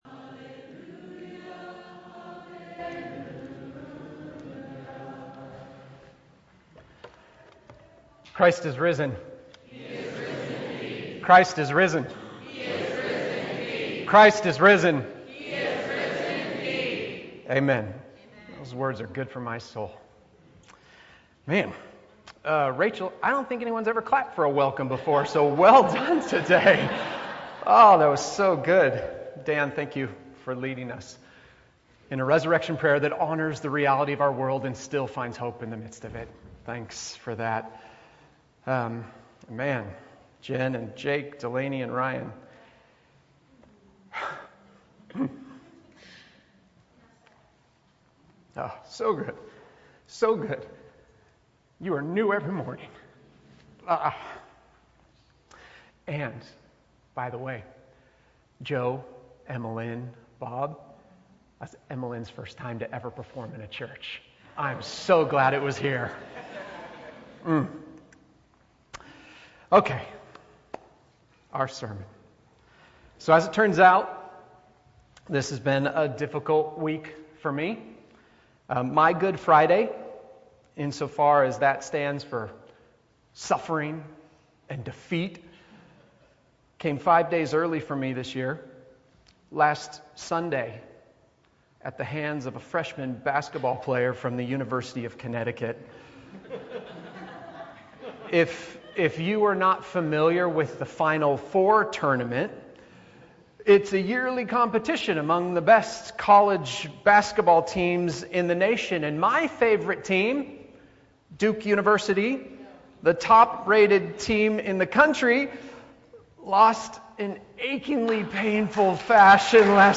A reflection on the resurrection for Easter Sunday